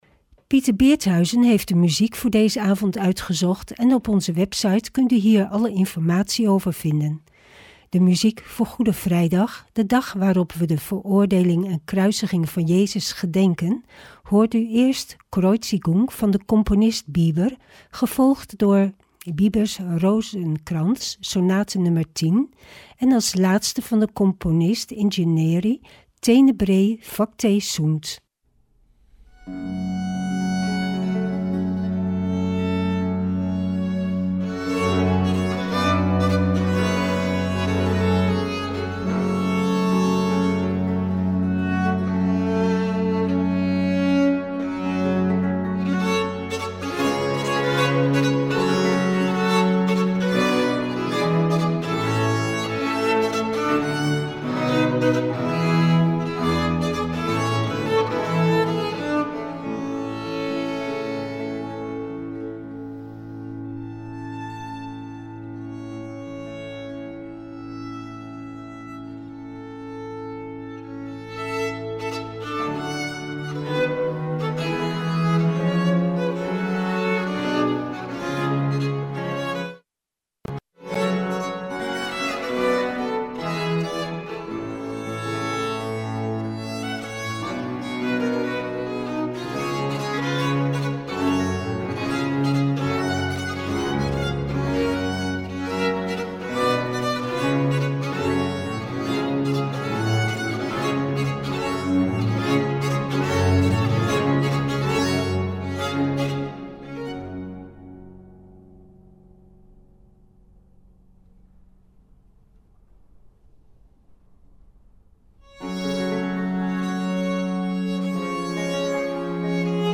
Opening van deze Goede Vrijdag met muziek, rechtstreeks vanuit onze studio.